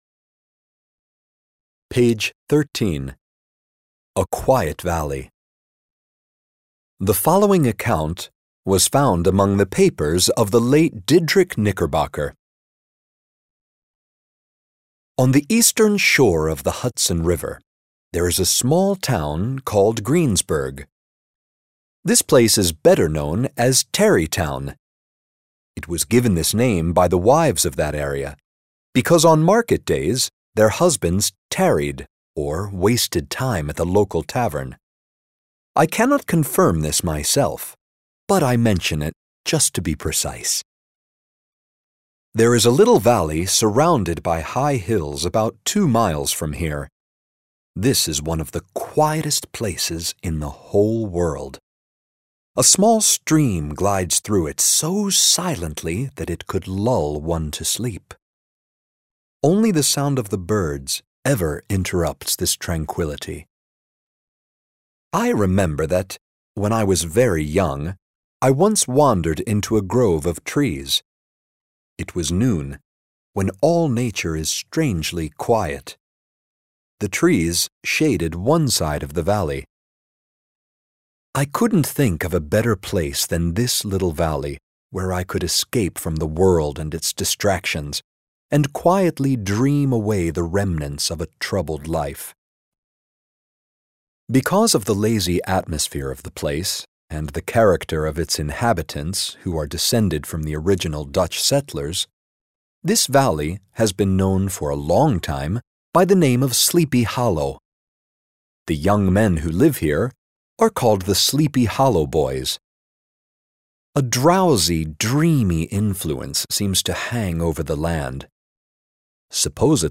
全系列皆隨書附贈全文朗讀MP3
【本書錄音採用美式發音】